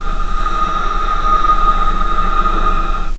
vc-D#6-pp.AIF